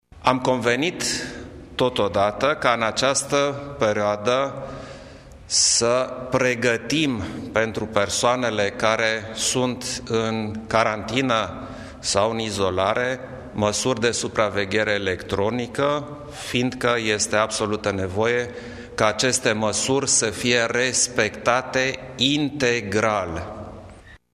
Șeful statului a anunțat măsuri speciale de protecție pentru persoanele aflate în carantină instituționalizată. Președintele Klaus Iohannis: